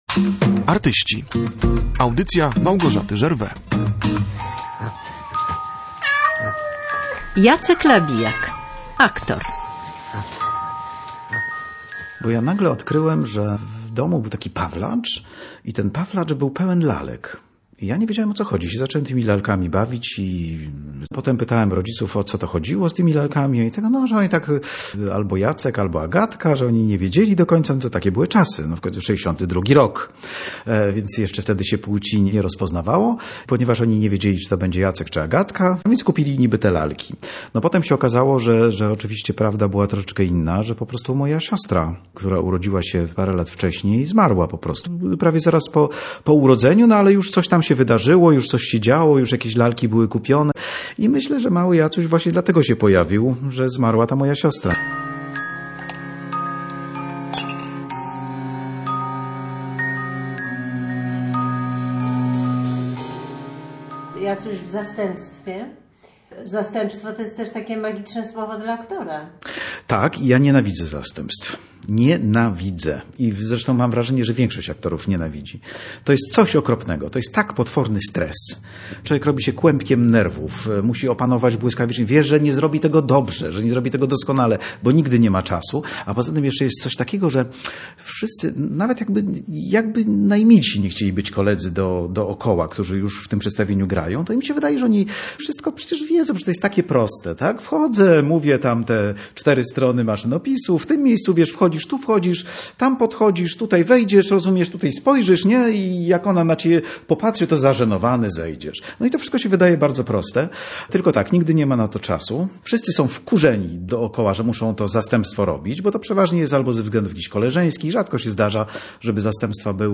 W teatralnej garderobie rozmawiamy o zawiłych losach aktorów i o dzieciństwie bohatera audycji, np. o upadku ze schodów z kotem w objęciach, co poskutkowało pewnymi komplikacjami (nie u kota).